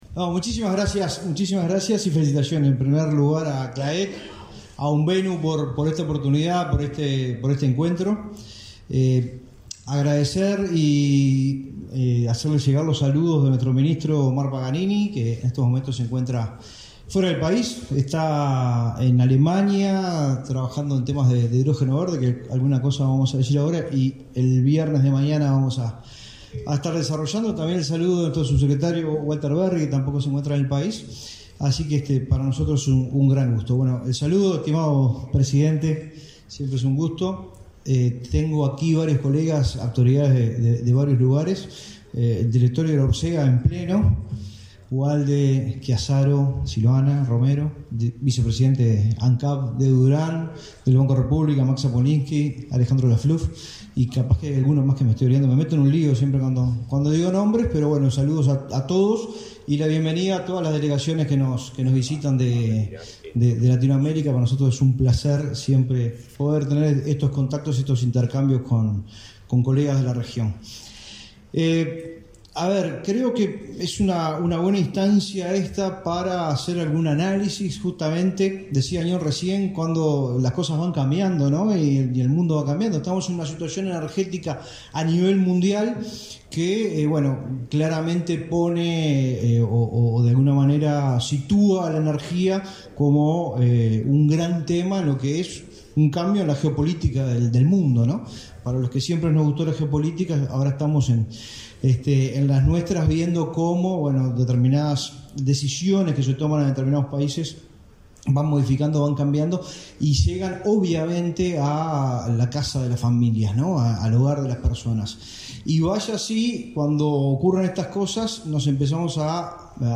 Palabras del director Nacional de Energía del MIEM, Fitzgerald Cantero
Palabras del director Nacional de Energía del MIEM, Fitzgerald Cantero 07/09/2022 Compartir Facebook X Copiar enlace WhatsApp LinkedIn Con la participación del presidente de la República, Luis Lacalle Pou, se realizó una nueva edición de la reunión de la Comisión Latinoamericana de Empresarios de Combustible (Claec), el 7 de setiembre.